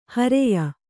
♪ hareya